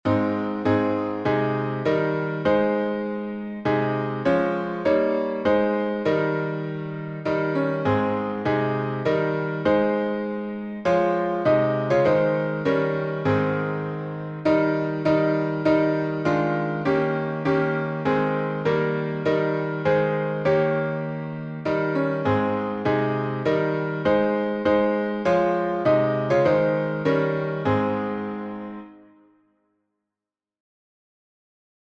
O Worship the King — in A flat with alternate chording.
Words by Robert Grant (1779-1838), 1833Tune: LYONS, J. Michael Haydn (1737-1806)Key signature: A flat major (4 flats)Time signature: 3/4Public DomainOrgan Performance at Hymns Without Words1.